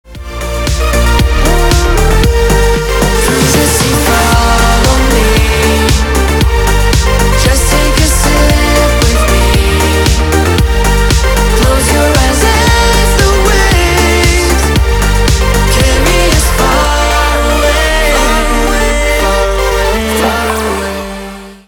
танцевальные
битовые , басы , качающие